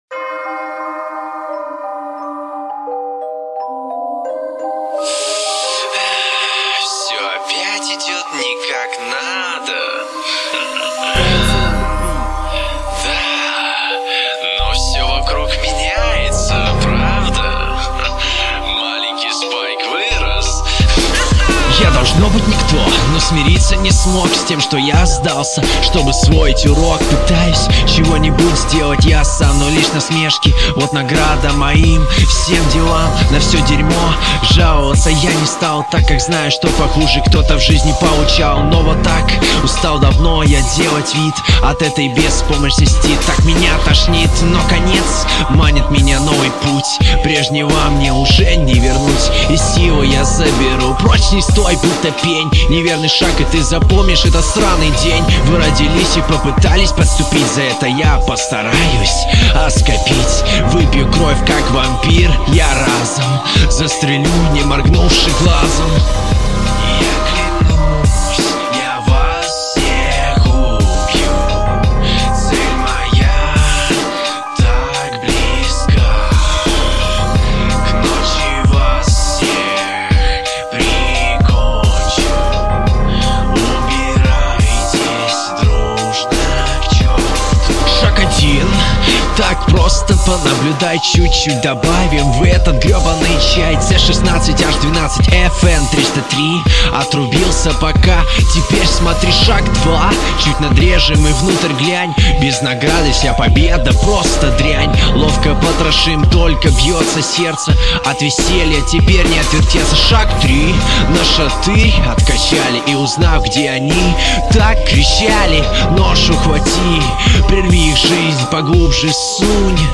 Довольно мрачная атмосфера